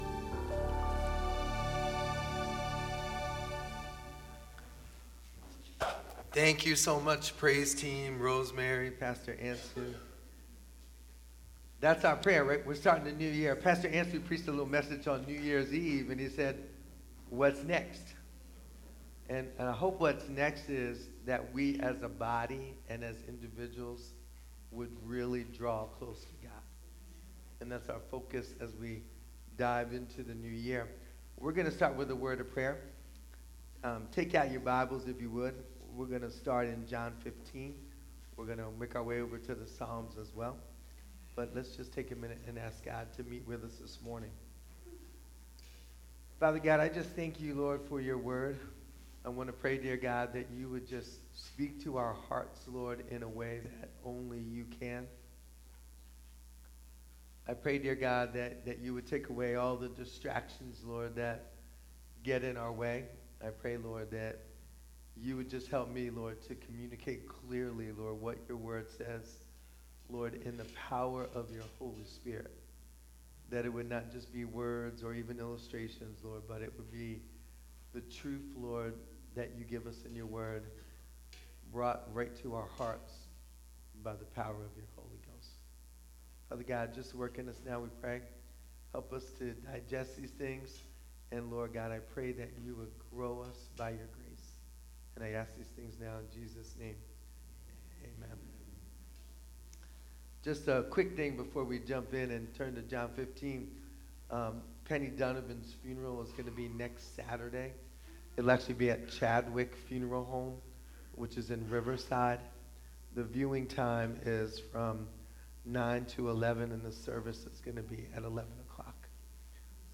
Sermons | First Baptist Church of Willingboro, NJ